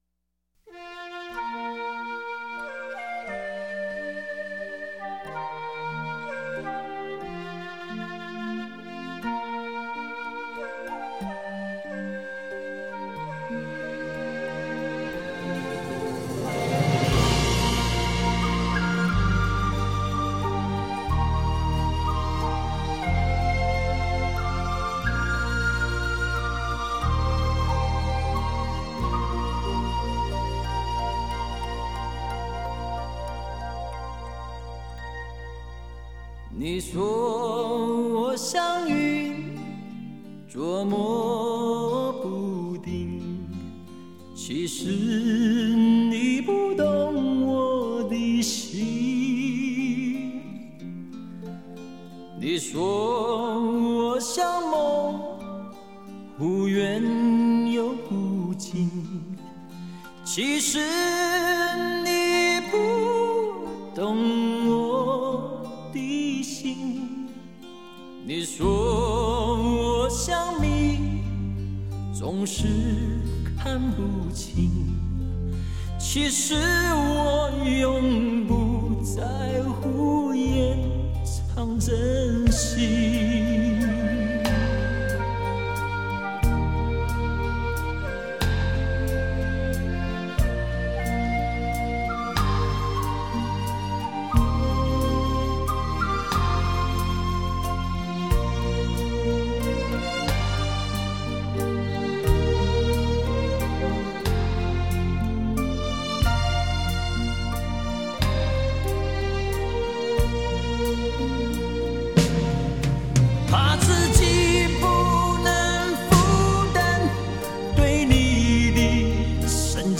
轻快的抒情曲风